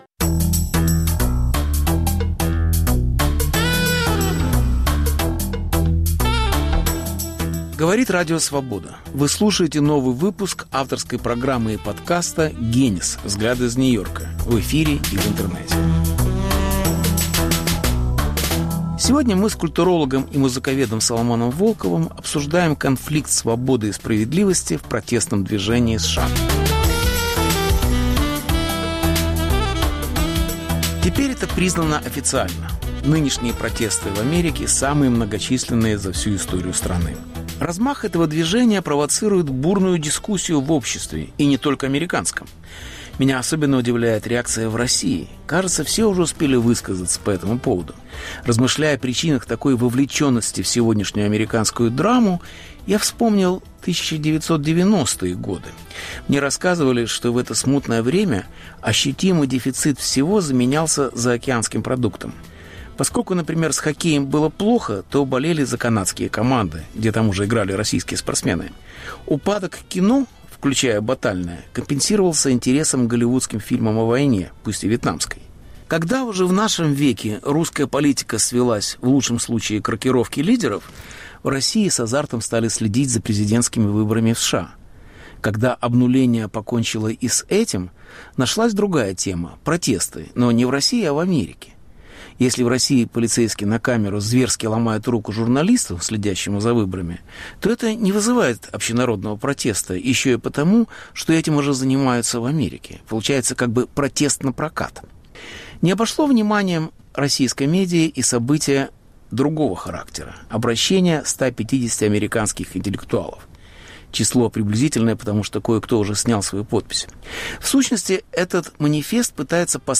Беседа с Соломоном Волковым о конфликте свободы и справедливости в протестном движении в США.